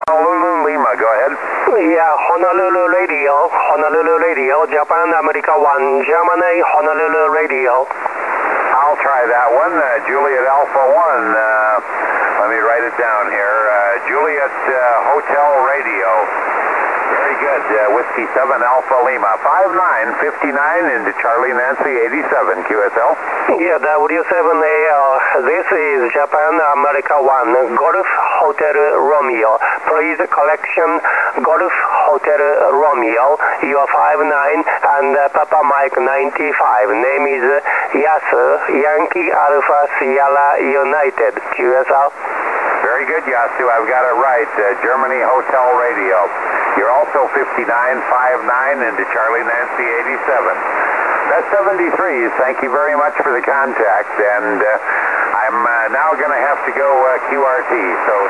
6m3(MP3 569KB)　　 SSBでWと交信しているJA1の局。Wがこんなに強い。